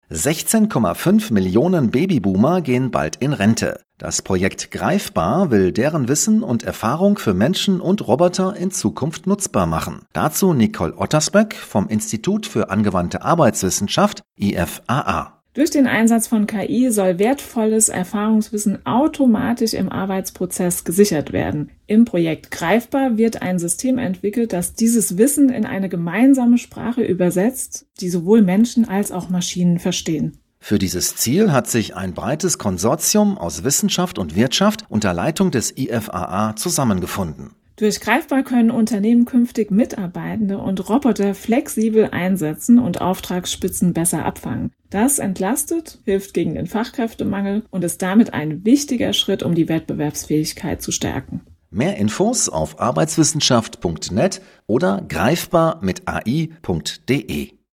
rts-beitrag-ki-projekt.mp3